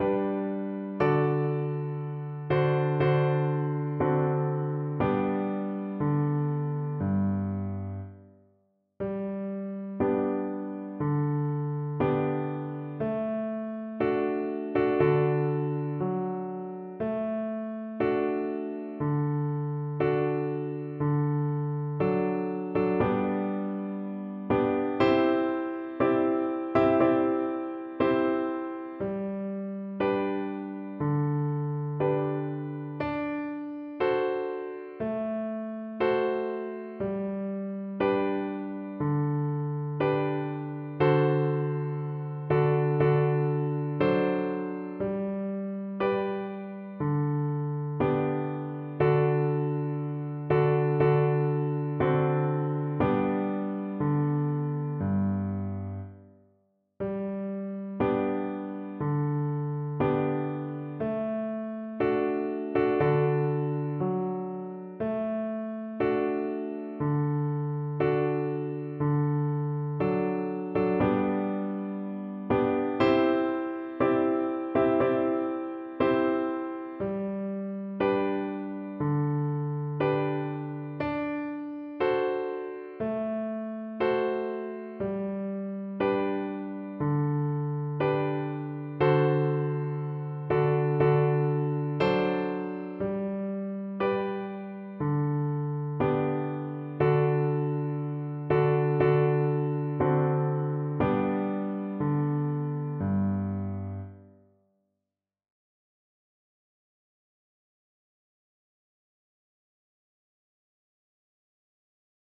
4/4 (View more 4/4 Music)
Moderato
F#5-A6